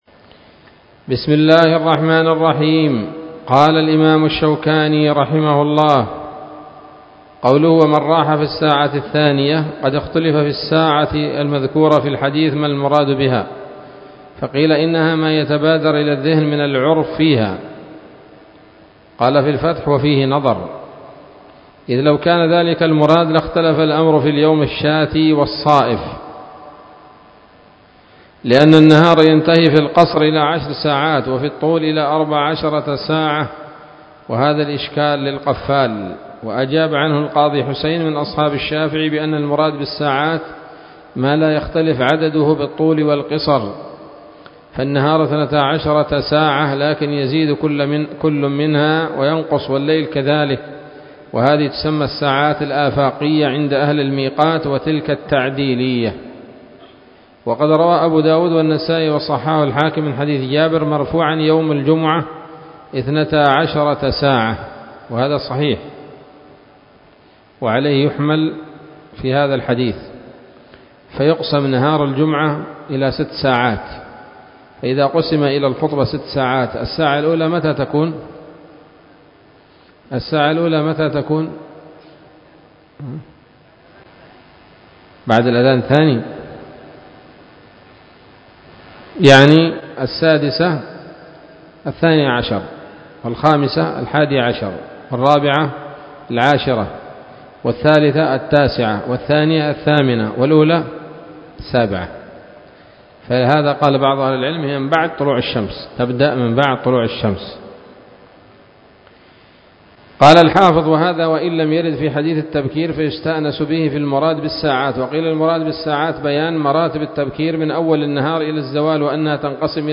الدرس العاشر من ‌‌‌‌أَبْوَاب الجمعة من نيل الأوطار